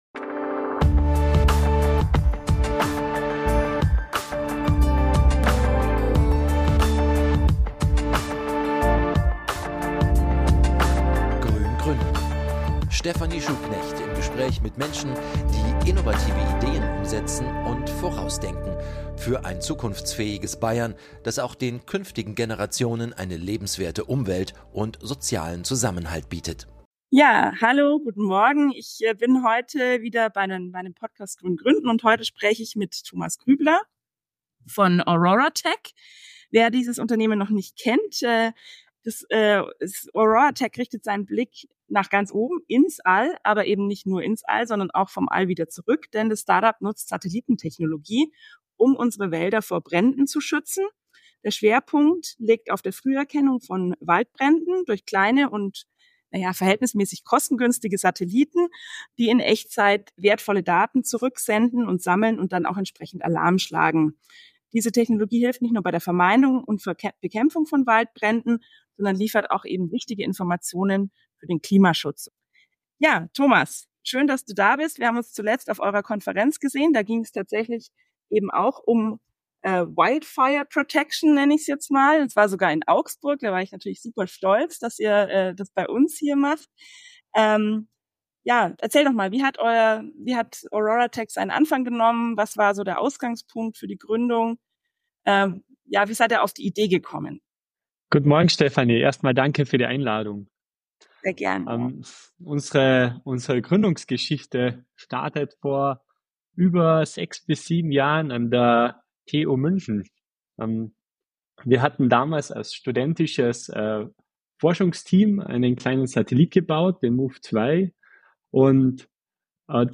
Episode 12 - Waldbrände aus dem Weltall verhindern ~ Grün Gründen: Stephanie Schuhknecht im Gespräch Podcast